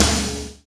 Index of /90_sSampleCDs/Roland L-CD701/KIT_Drum Kits 5/KIT_Induced Kit
SNR INDUC03R.wav